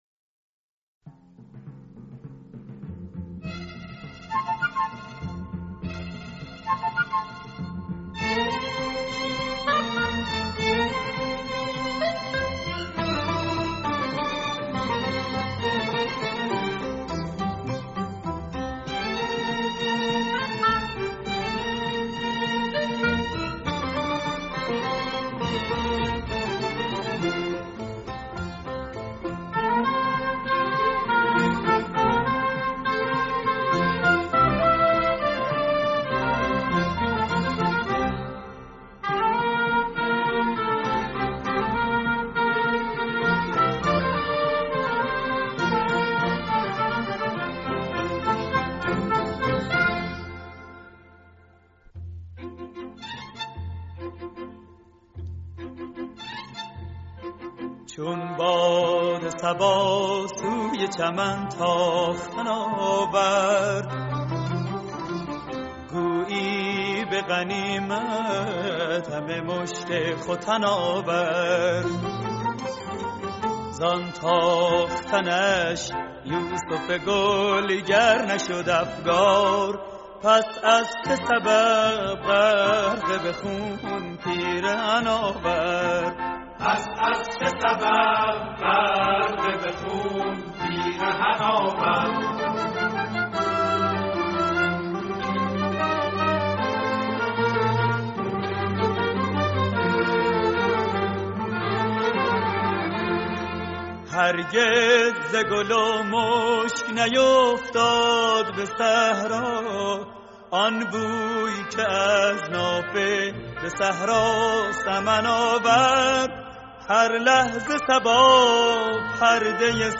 تصنیف